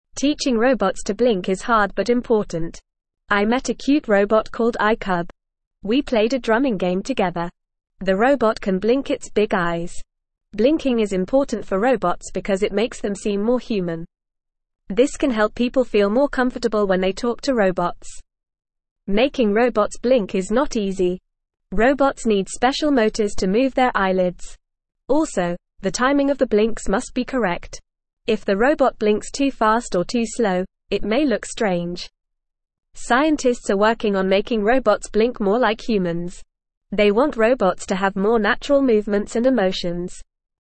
Fast
English-Newsroom-Beginner-FAST-Reading-Robots-Learn-to-Blink-for-Human-Comfort.mp3